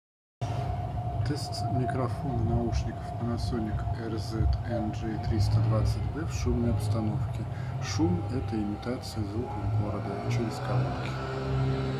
🎙 Микрофон
Микрофон тут расположен удобно, хорошо отсекает окружающие шумы.
Ниже пара записей на диктофон – оцените сами.
Panasonic RZ-NJ320B: микрофон в тихой обстановке Panasonic RZ-NJ320B: микрофон в шумной обстановке 🔊 Качество звука По звуку у Panasonic RZ-NJ320B откровений нет .